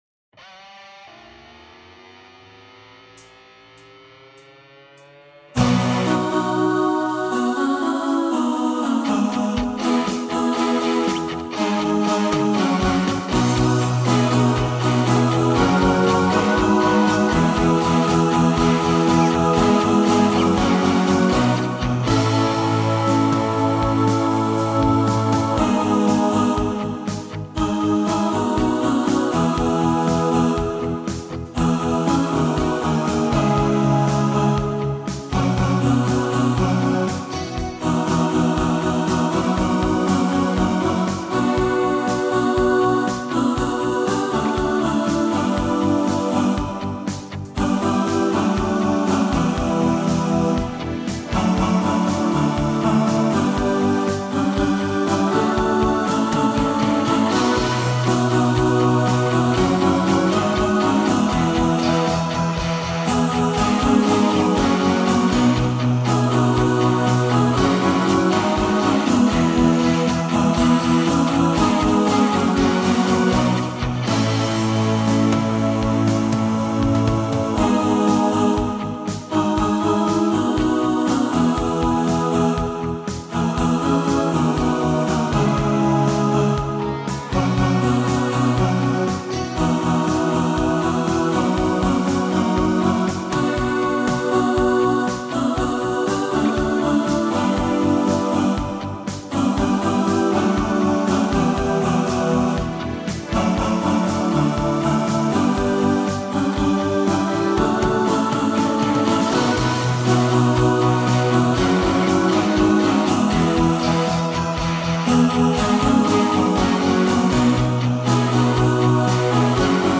The-One-And-Only-All-Voices.mp3